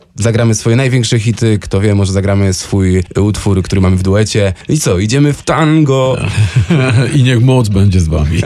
Gwiazdy polskiej sceny muzycznej, Jacek Stachursky i Paweł Lipski, czyli Nowator są już w Stanach Zjednoczonych i odwiedzili studio Radia Deon.